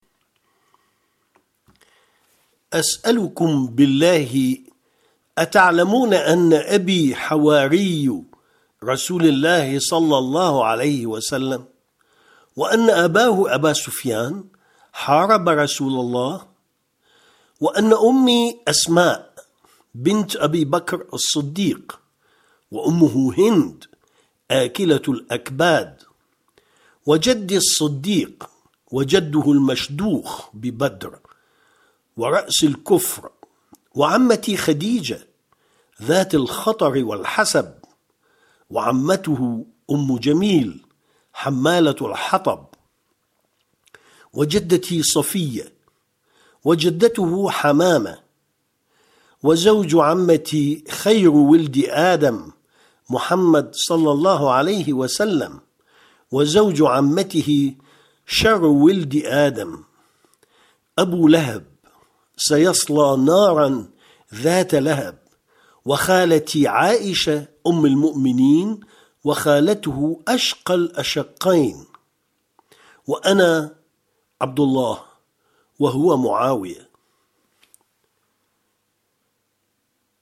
- Il se peut que des erreurs de prononciation se présentent dans les documenst sonores ; une version corrigée sera mise en ligne par la suite.